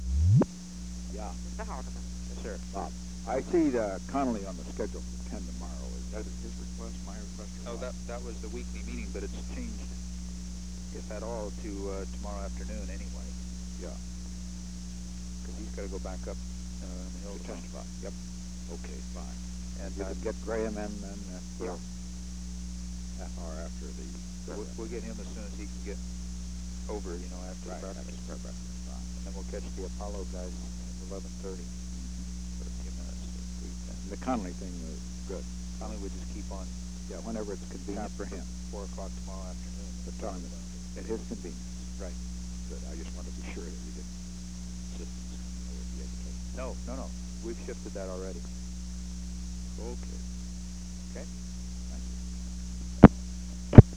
Secret White House Tapes
Location: White House Telephone
The President talked with H. R. (“Bob”) Haldeman